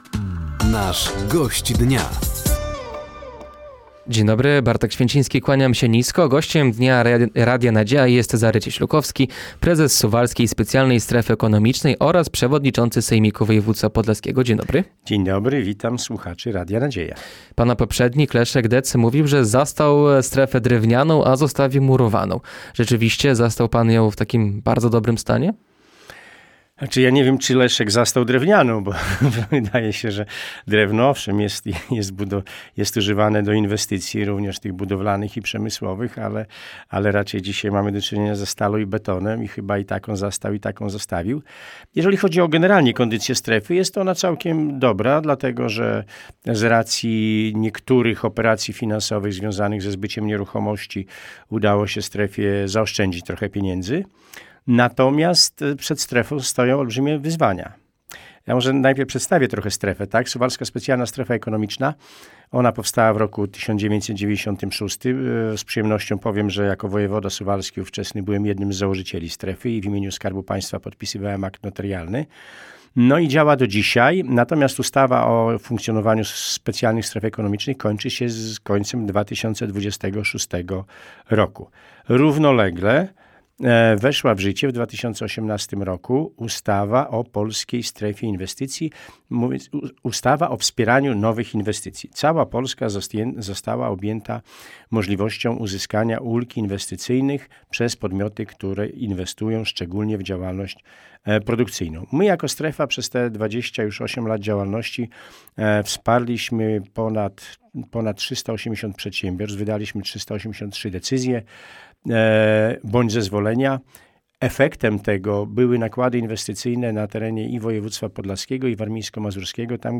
Gościem Dnia Radia Nadzieja był Cezary Cieślukowski, prezes Suwalskiej Specjalnej Strefy Ekonomicznej i przewodniczący sejmiku województwa podlaskiego.